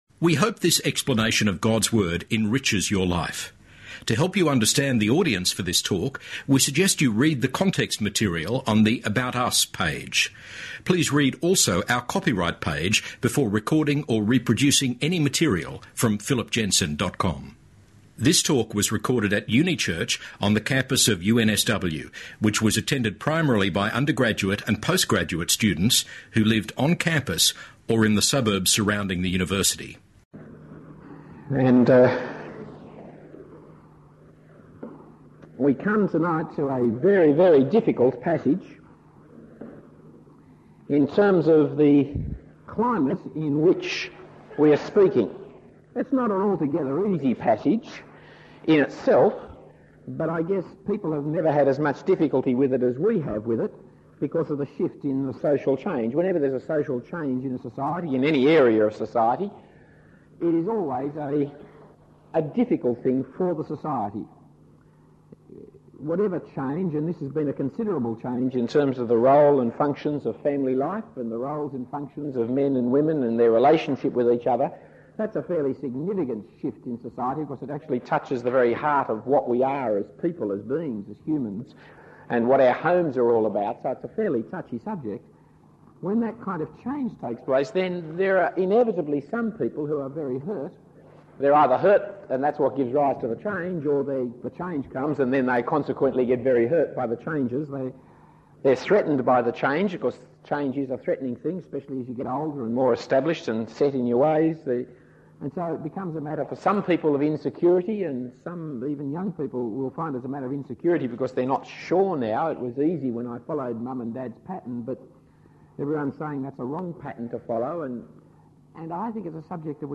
A talk given at Unichurch at UNSW in 1988.